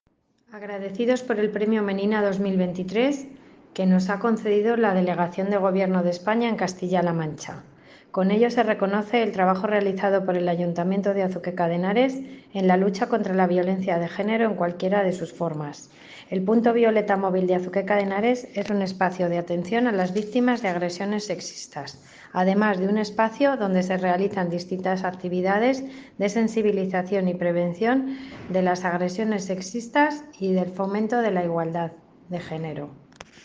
Declaraciones de la concejala Piedad Agudo